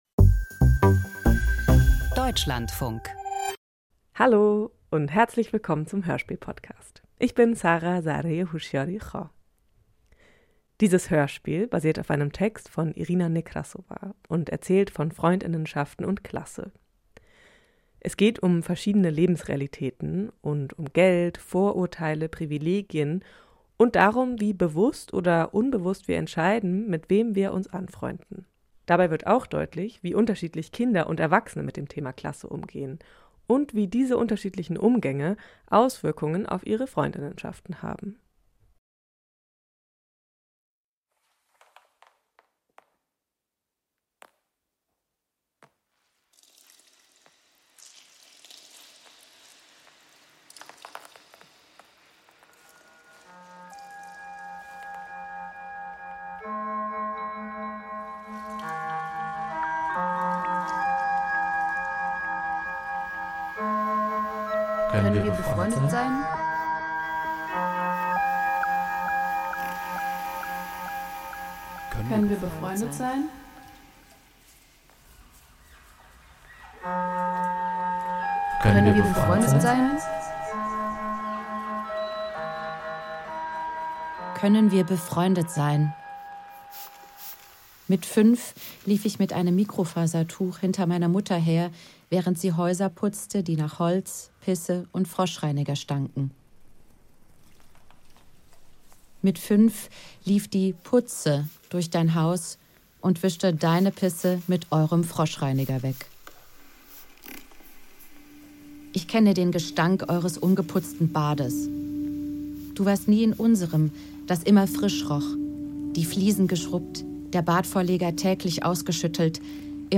Biographisches Hörstück über Klassismus - Freunde
Im Doku-Hörspiel reflektieren Menschen mit Armutserfahrung über Klasse und ihren Einfluss auf alle Lebensaspekte – auch Freundschaften.